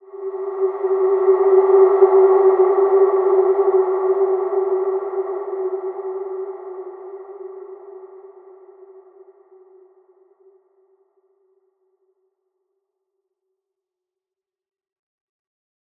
Large-Space-G4-f.wav